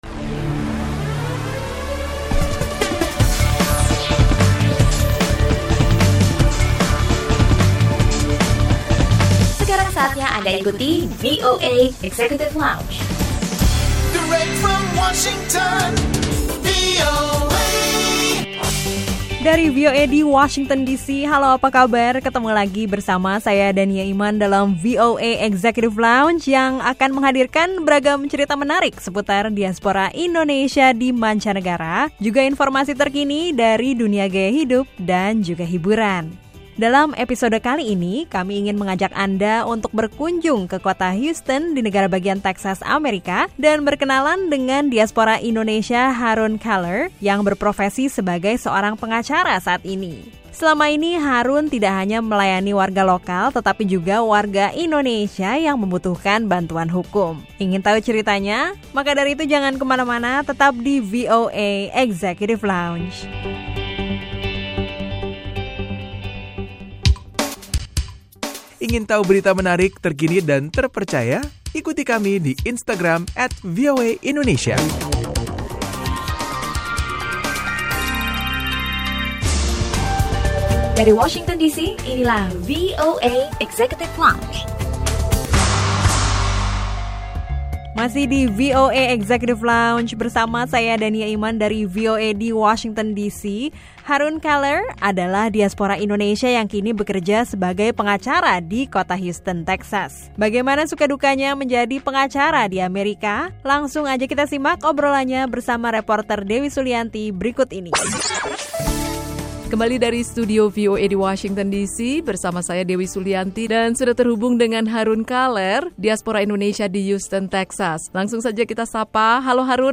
Obrolan bersama pengacara keturunan Indonesia